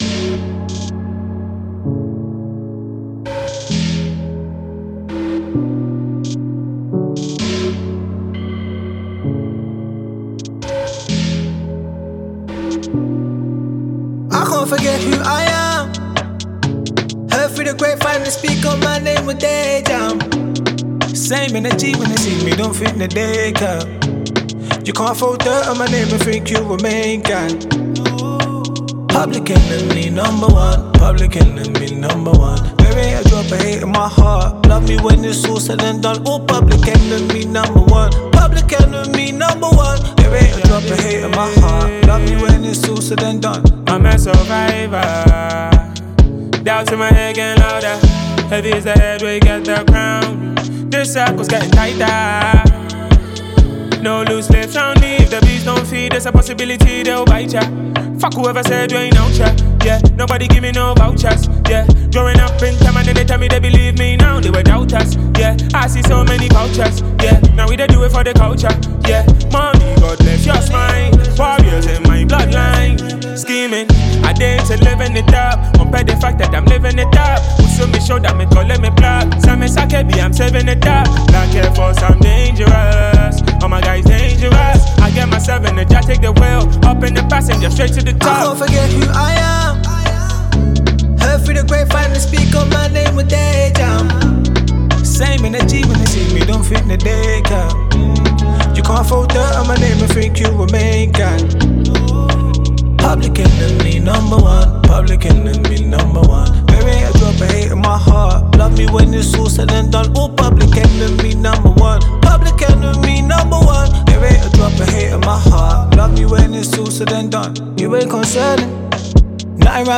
award-winning Ghanaian rapper